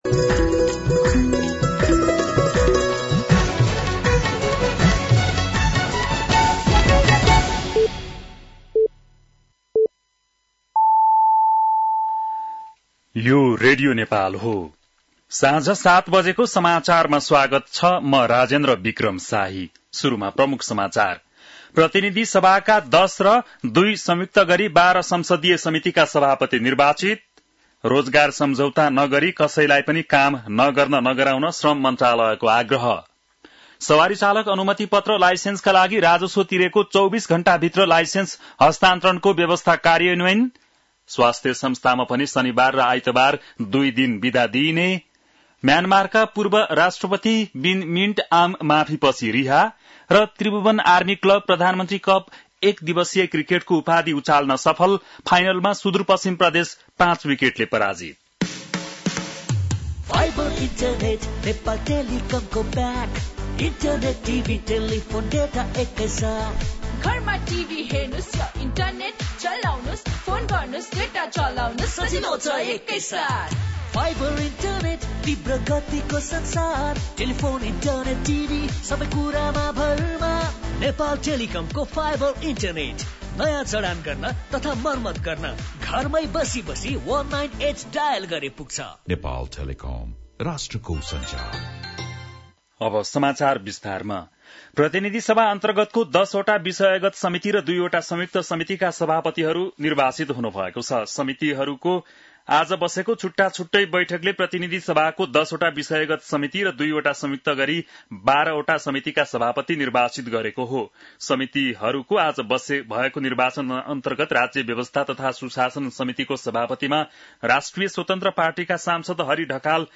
बेलुकी ७ बजेको नेपाली समाचार : ४ वैशाख , २०८३